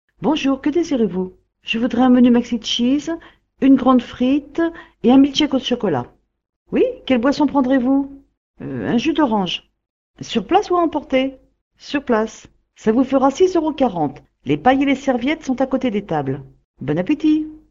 restaurant.mp3